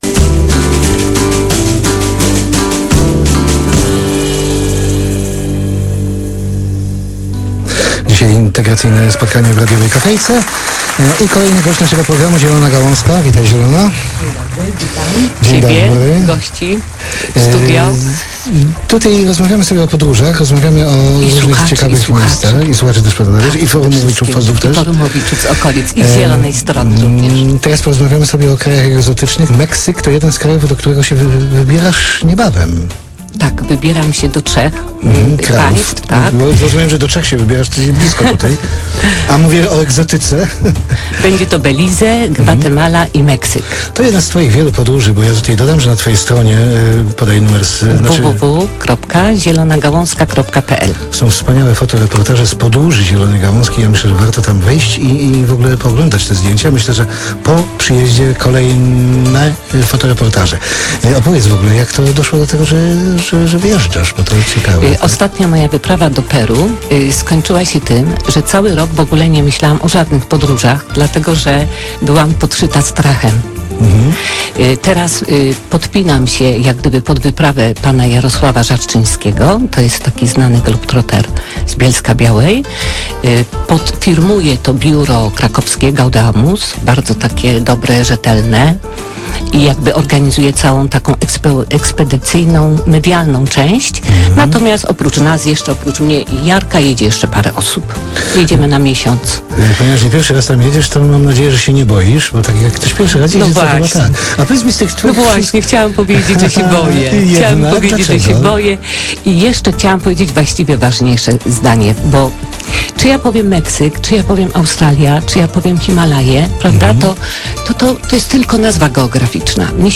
trzecie spotkanie forumowiczów z Okolic Bluesa.